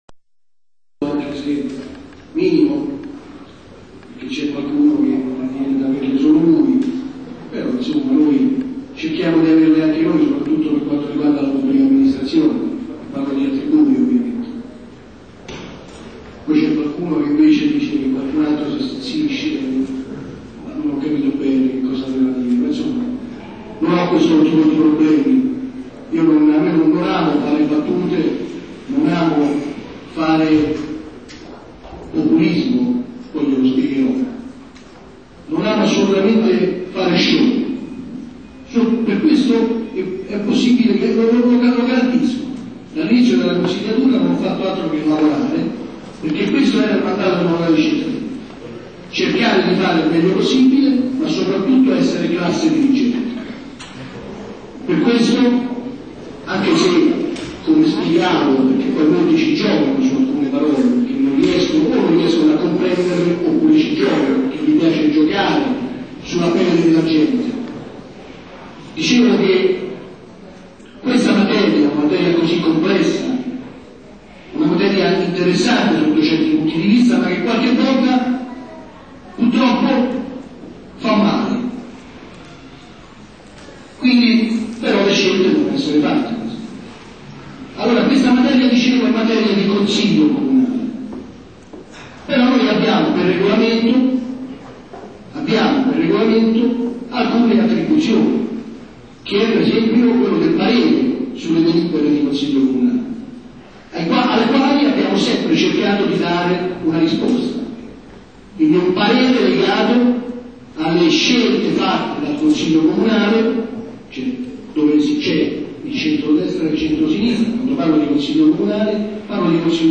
Consiglio Municipale del 31 ottobre 2003 per la proposta di osservazioni al Piano.
Archivio sonoro degli interventi.
Consigliere del Municipio 8°
Intervento